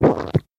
Звук глотания слюны человеком